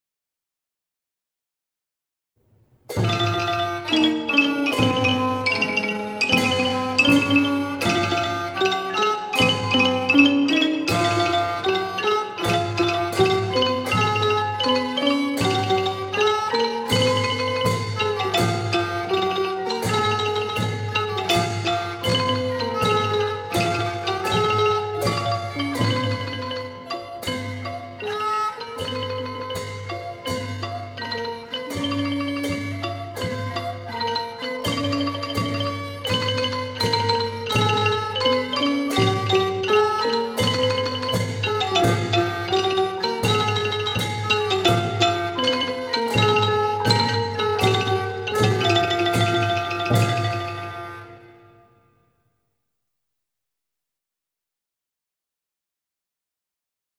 เพลงมหาชัย (วงปี่พาทย์-ไม้แข็ง)